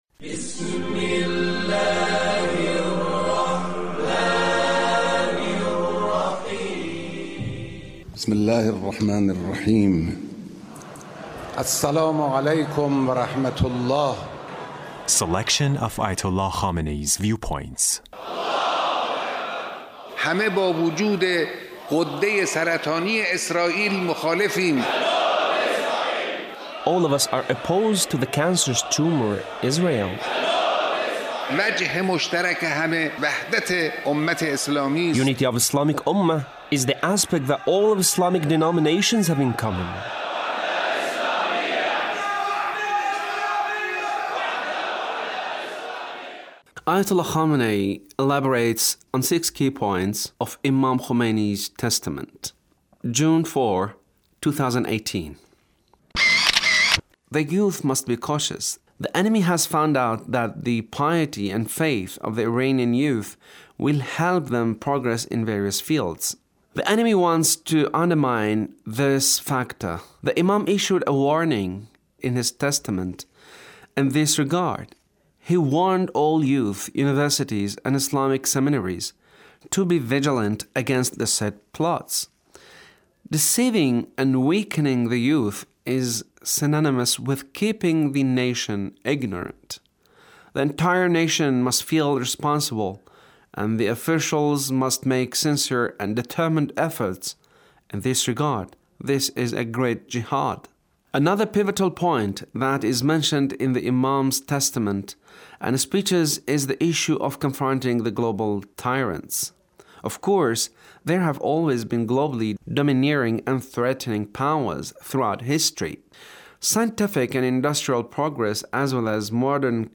Leader's speech (1422)